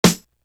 Going Pop Snare.wav